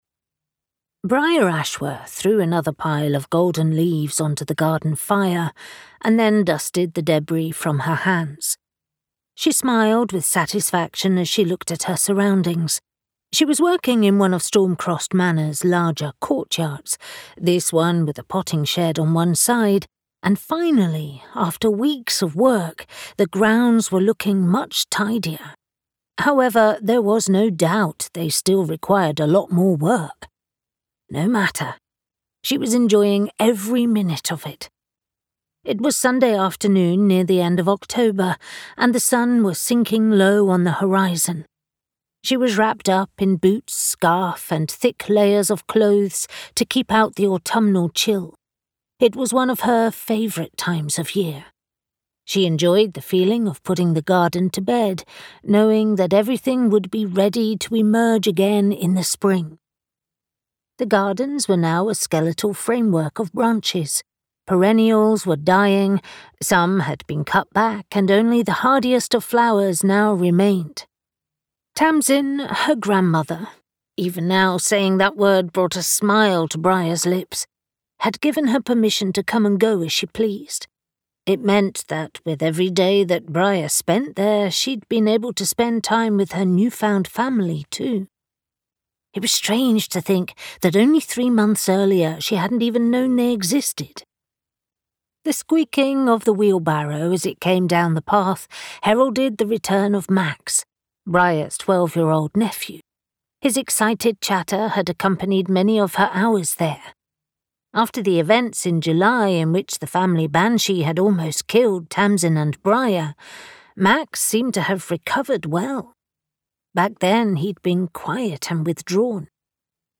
Wyrd Magic Audiobook